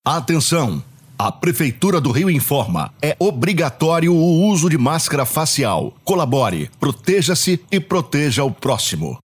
Prefeitura do Rio acrescenta mensagem para o drone falar: use máscara
Agora, o equipamento emite a orientação pelo uso obrigatório de máscara, conforme decreto do prefeito Marcelo Crivella para aumentar a prevenção ao contágio da Covid-19.